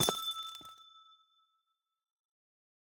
Minecraft Version Minecraft Version 1.21.5 Latest Release | Latest Snapshot 1.21.5 / assets / minecraft / sounds / block / amethyst / step3.ogg Compare With Compare With Latest Release | Latest Snapshot
step3.ogg